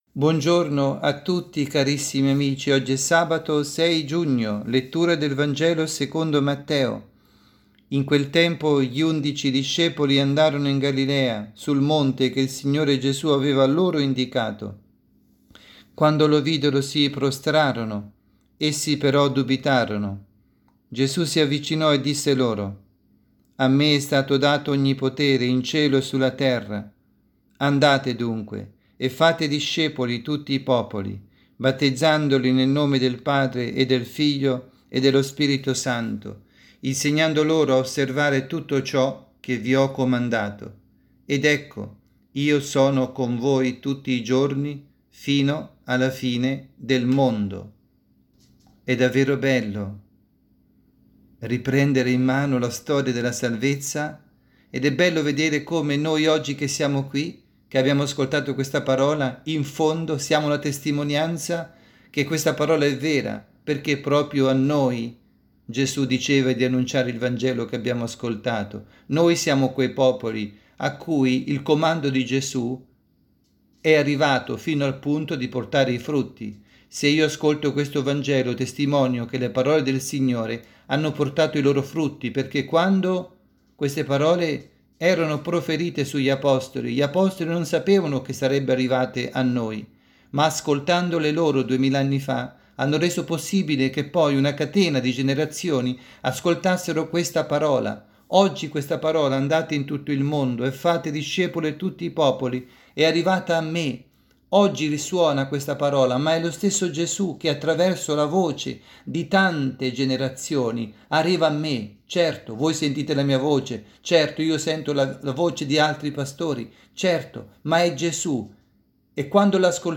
Catechesi
dalla Parrocchia S. Rita – Milano